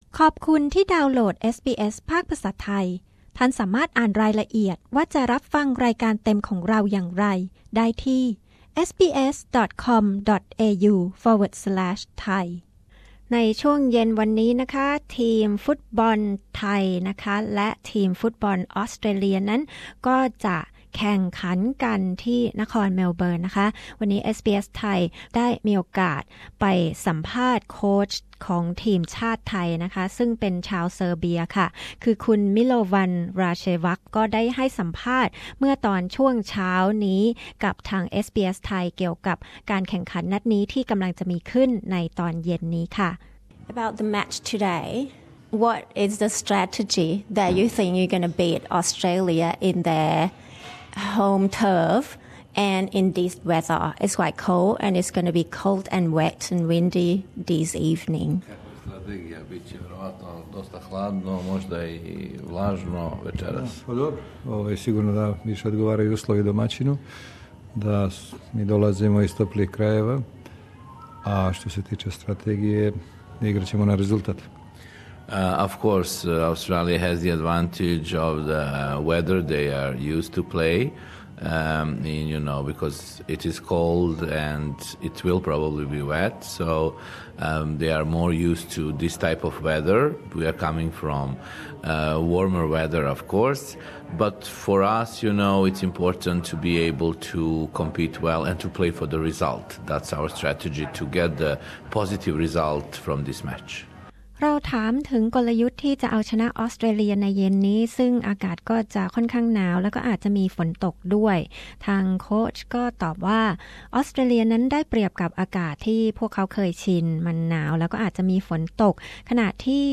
สัมภาษณ์พิเศษโค้ชและผู้เล่นของทีมฟุตบอล ไทย ก่อนลงเล่นปะทะทีมออสเตรเลีย ที่นครเมลเบิร์น เย็นนี้ 5 กันยายน